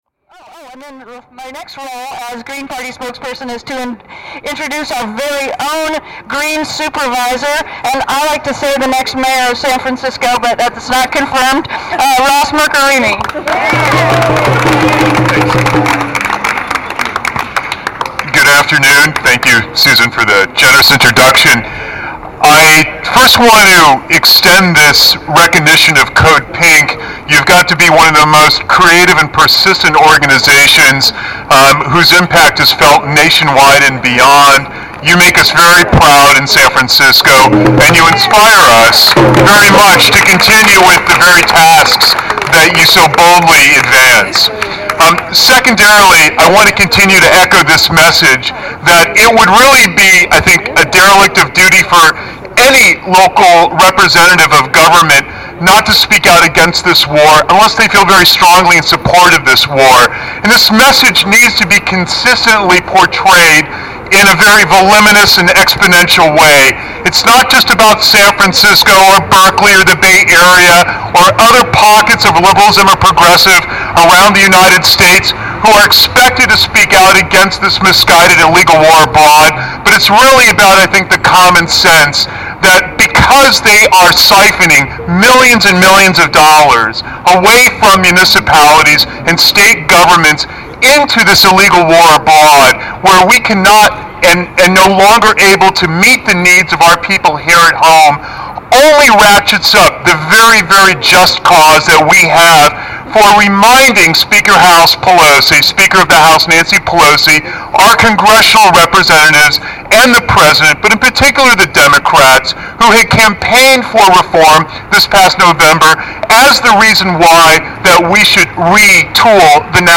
Meeting at the foot of San Francisco's Federal Building this morning, San Francisco Supervisors Ross Mirkarimi and Chris Daly joined other speakers to demand that Congresswoman and Speaker of the U.S. House of Representatives Nancy Pelosi honor their resolution and the opinion of a majority of citizens to discontinue funding military operations and hold the Bush administration accountable for its total failure in Iraq.
Following are some more photos of speakers in chronological order with short mp3 recordings of their statements.
§City Supervisor Ross Mirkarimi (mp3)